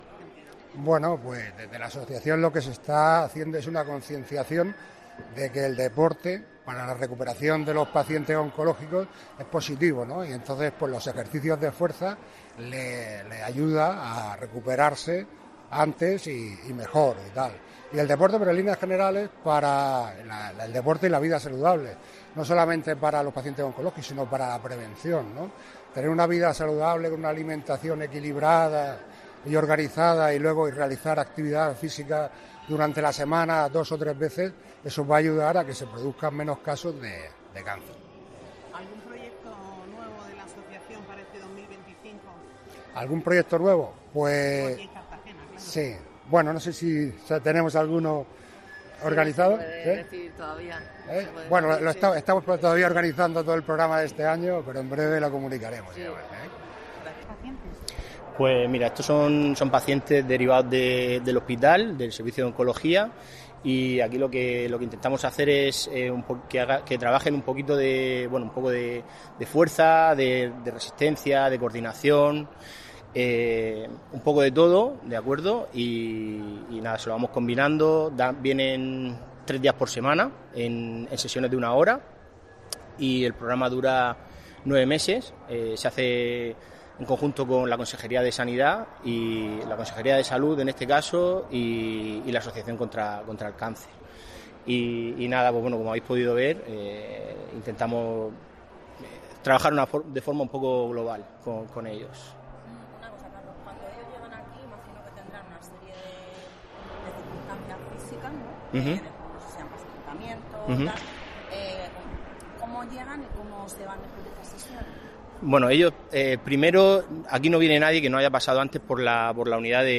Enlace a Declaraciones de la alcaldesa, Noelia Arroyo, sobre programa Activa Suma